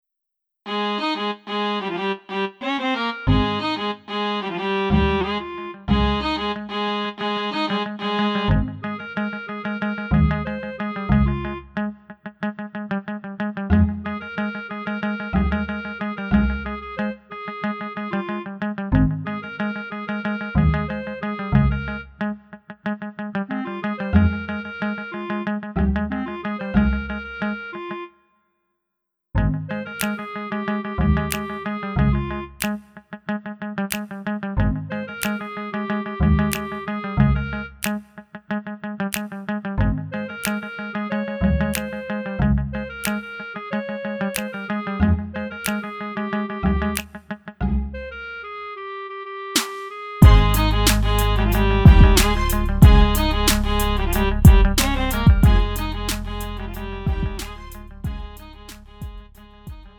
음정 -1키 3:19
장르 가요 구분 Lite MR